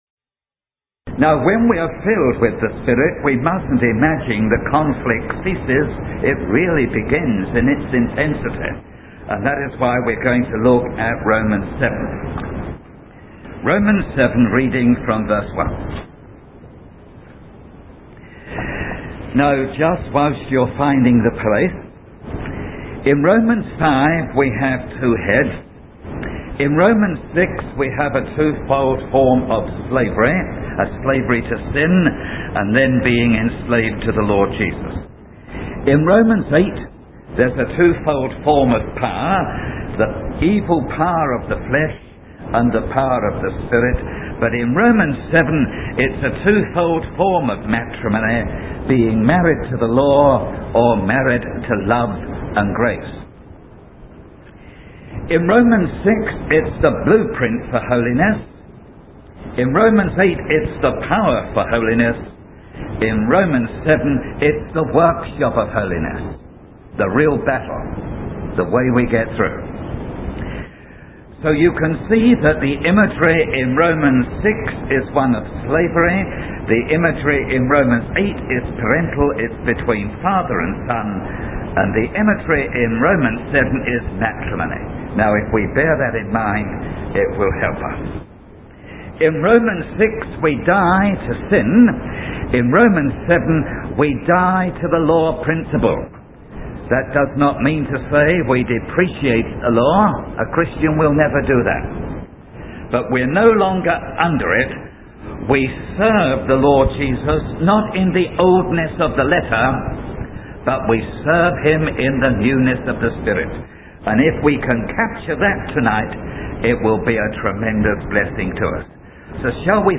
The sermon ultimately calls for a deeper understanding of grace and the transformative power of the Holy Spirit in the life of a Christian